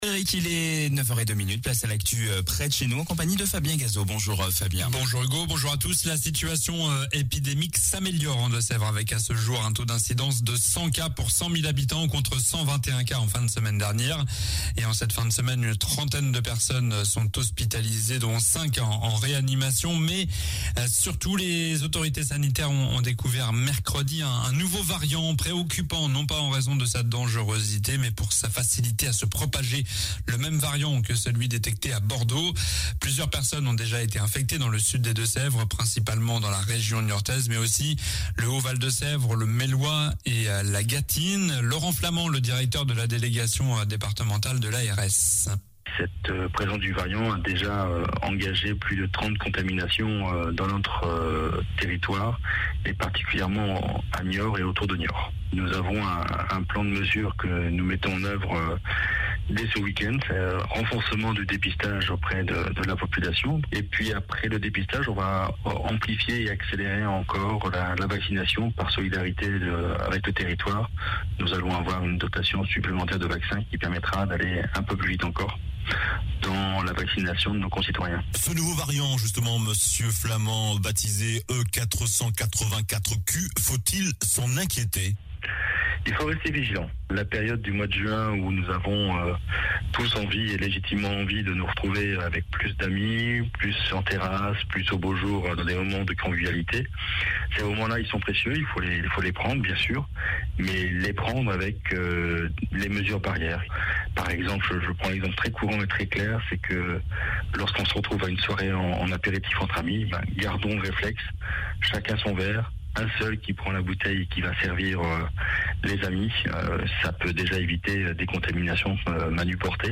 Journal du samedi 29 mai